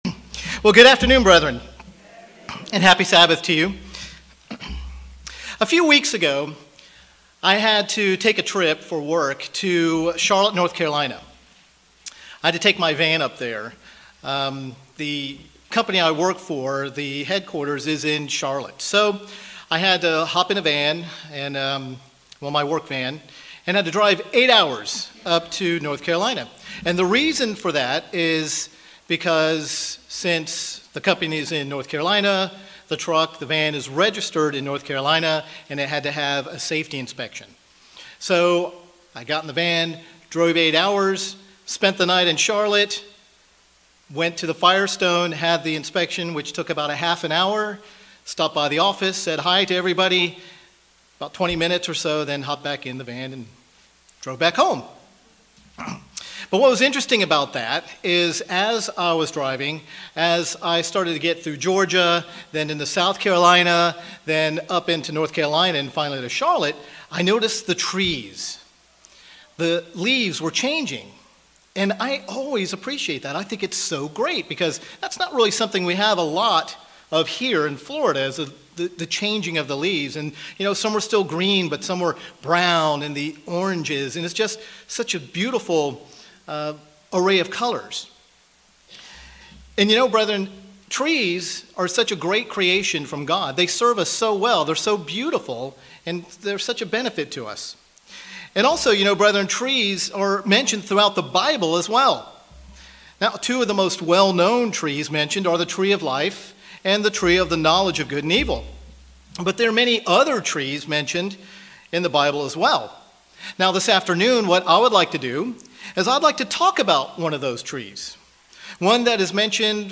Sermons
Given in Orlando, FL